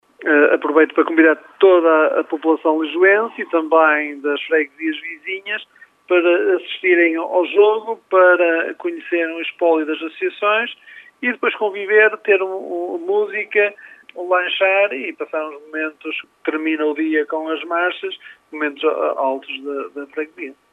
Filipe Oliveira, presidente da junta de Lijó aproveita para reforçar o convite